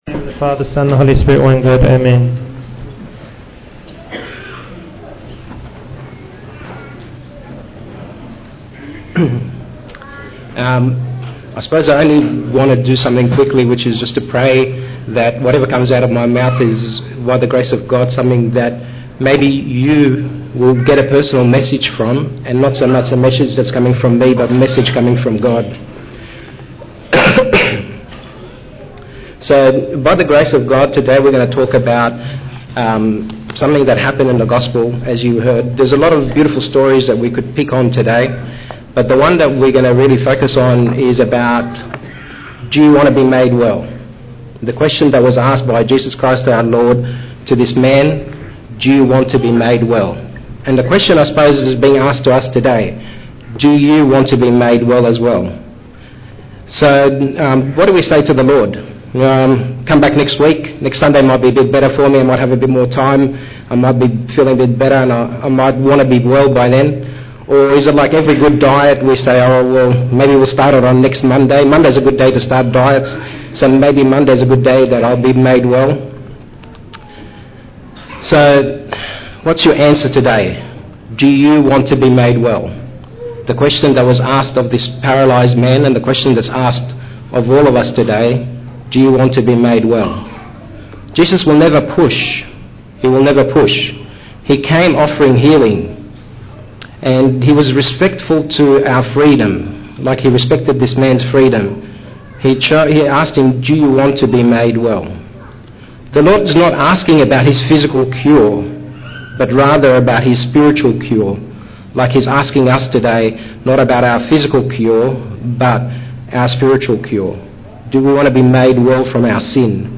John 5:1-18 Service Type: English Sunday Sermon Bible Text